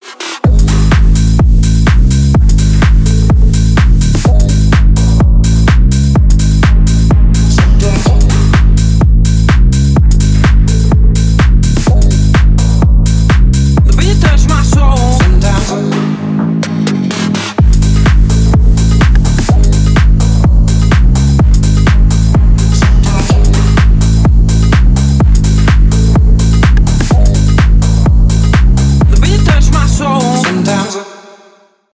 Рэп и Хип Хоп
клубные # громкие